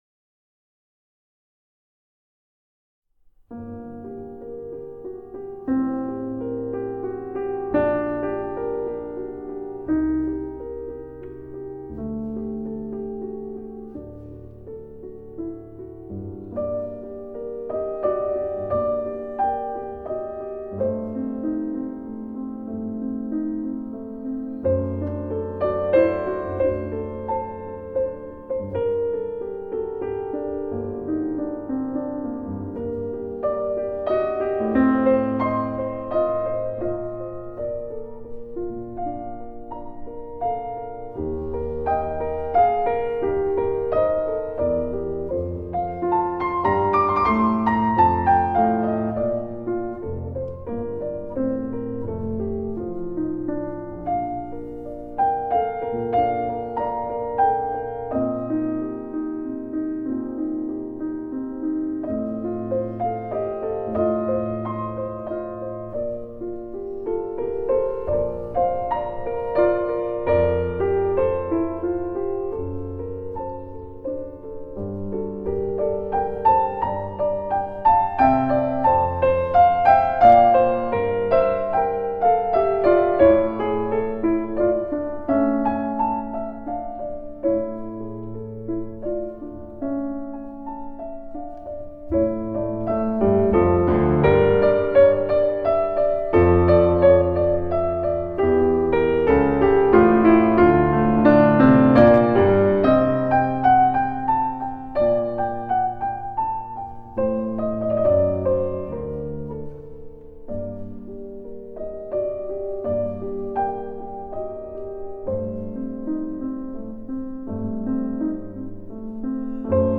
Flute Sonata No. 2, BWV. 1031 “Siciliano” by J.S. Bach (trancr. Kempff), performed by Lang Lang:
lang-lang-bach-flute-sonata-in-e-flat-major-bwv-1031-ii.-siciliano-transc.-kempff.mp3